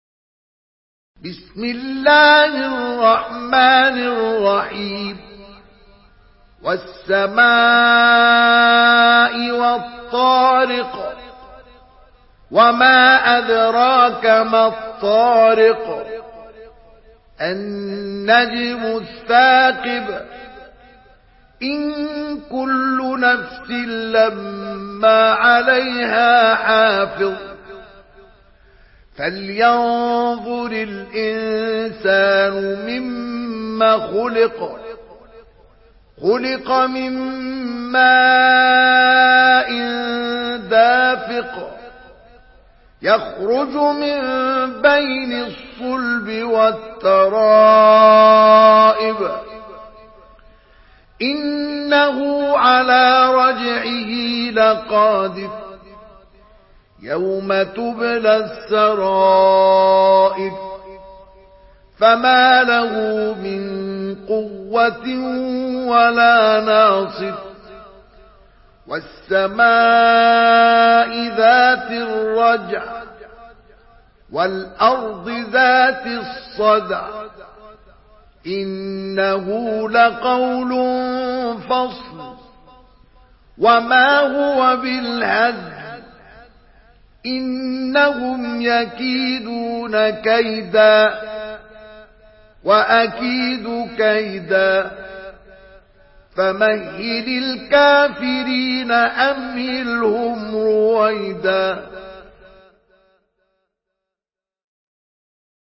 Surah Tarık MP3 by Mustafa Ismail in Hafs An Asim narration.
Murattal